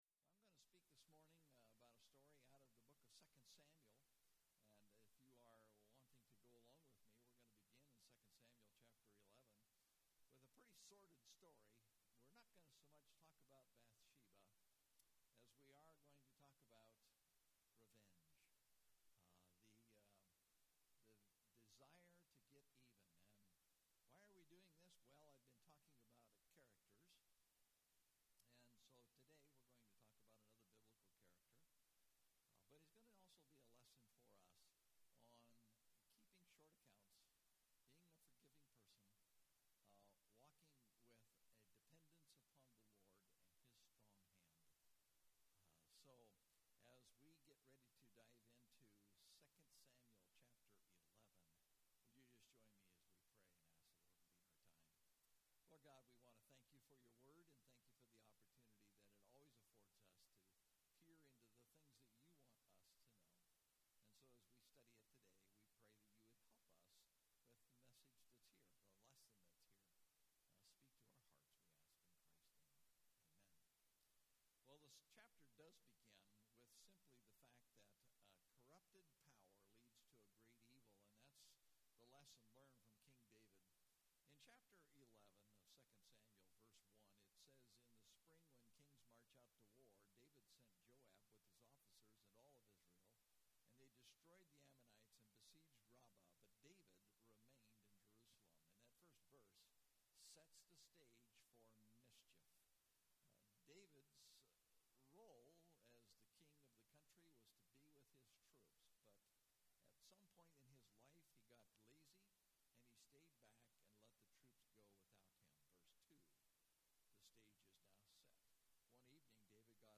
Online Church Service